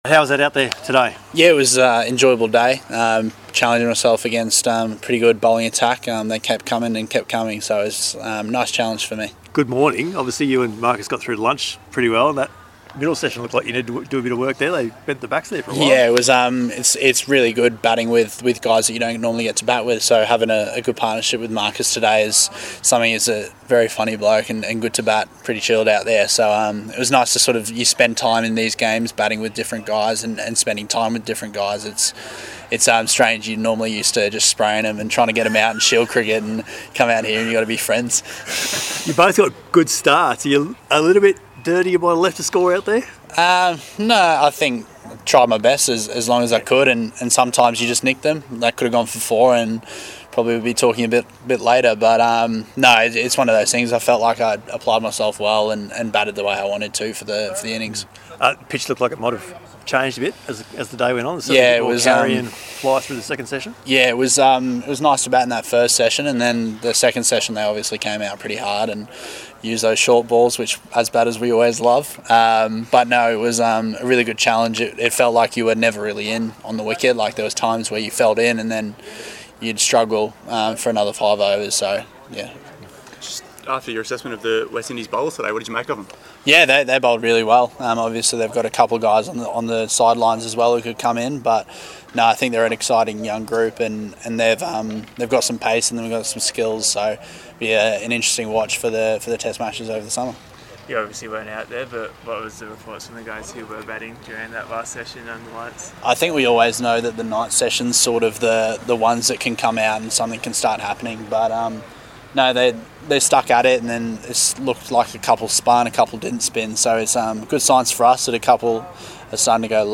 Matt Renshaw speaking after his 81 on Day 1 at Manuka Oval, where the PM's XI finished the day 9/297